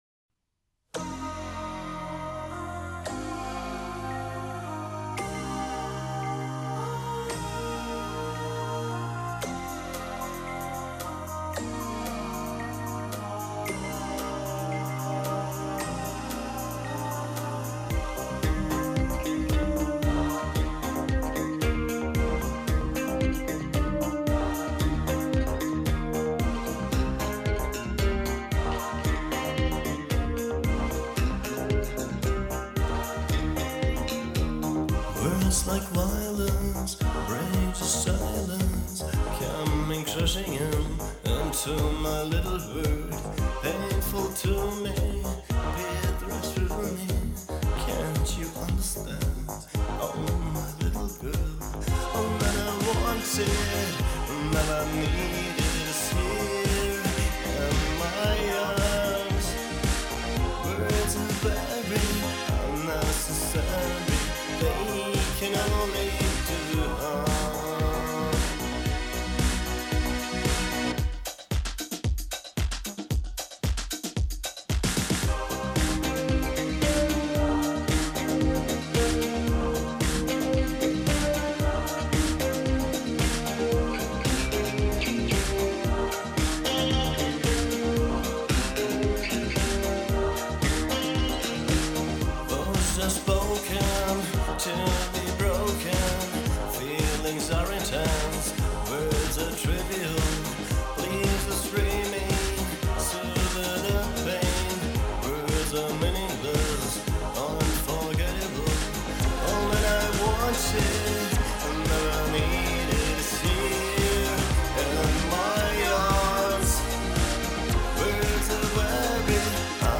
(kein Imitator, eigener Drive)